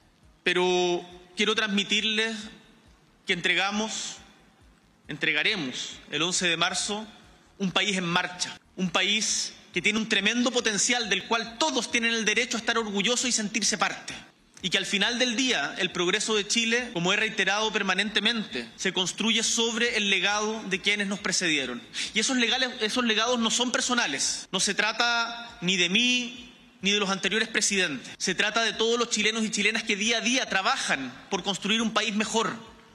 El Presidente de la República, Gabriel Boric Font, afirmó que el próximo 11 de marzo entregará “un país en marcha”, tras referirse a los resultados electorales en una declaración realizada desde el Palacio de La Moneda, acompañado por el Comité Político.